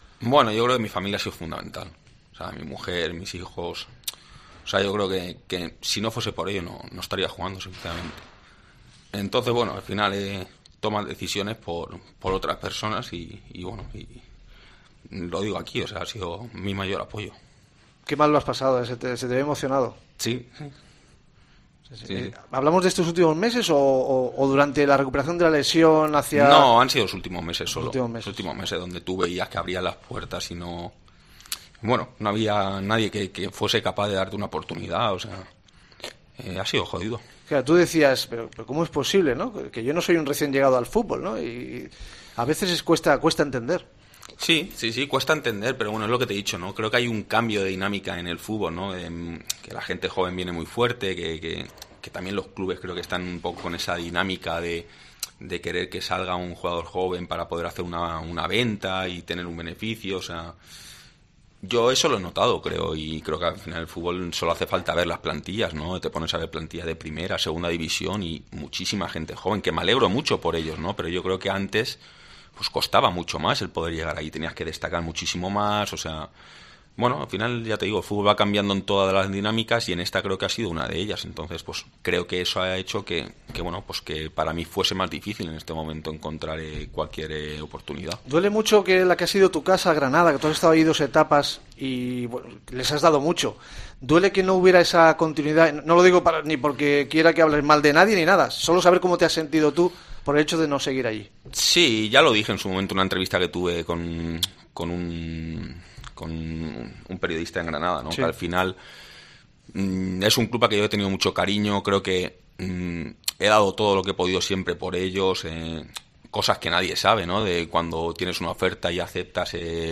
Y ahí se emociona al recordar el apoyo de su familia .